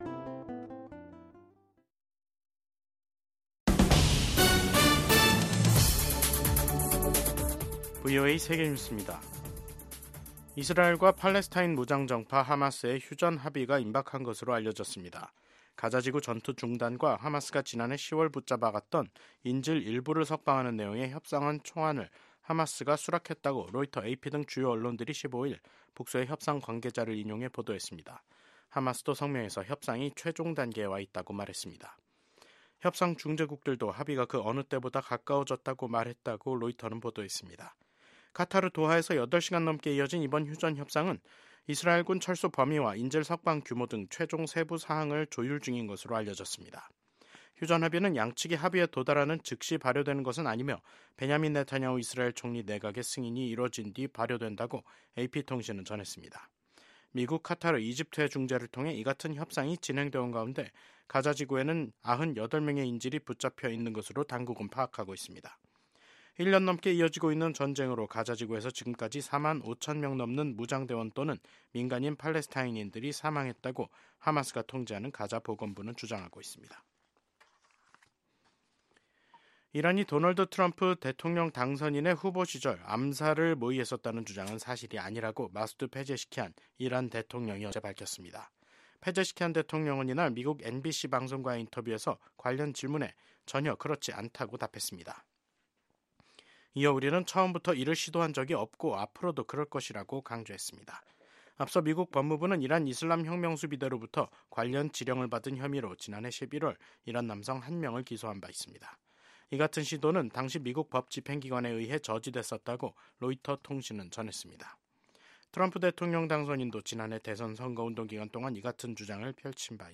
VOA 한국어 간판 뉴스 프로그램 '뉴스 투데이', 2025년 1월 15일 3부 방송입니다. 비상계엄 선포로 내란죄 혐의를 받고 있는 윤석열 대통령이 현직 대통령으론 한국 헌정사상 처음 사법기관에 체포됐습니다. 미국 백악관은 윤석열 한국 대통령이 체포된 데 대해 “미국은 한국 국민에 대한 지지를 확고히 한다”고 밝혔습니다. 미국의 전문가는 트럼프 정부가 혼란 상태에 빠진 한국 정부와 협력하는 것은 어려울 것이라고 전망했습니다.